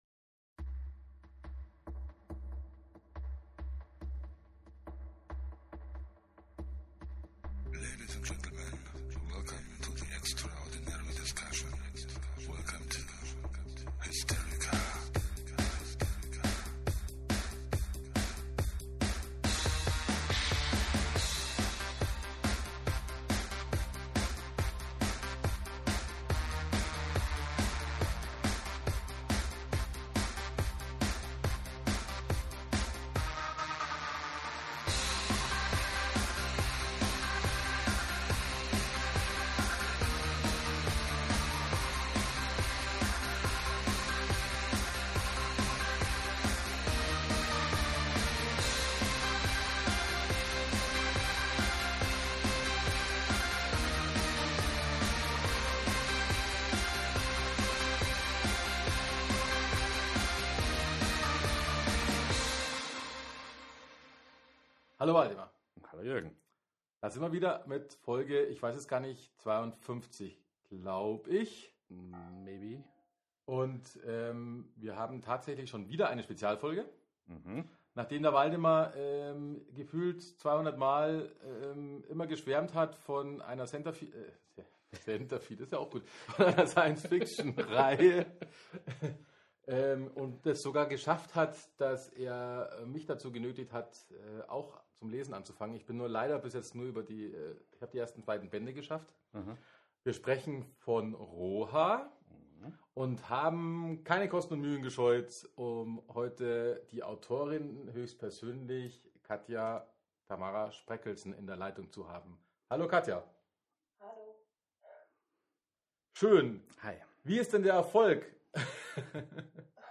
Kleine Anmerkung: Leider ist die Tonqualität diesmal eher als nicht herausragend zu benennen.
Das Eingangssignal war leider ein wenig dünn in seiner Leistung.
Spaß hat es erneut gemacht - Euch hoffentlich trotz der etwas schlechteren Qualität auch.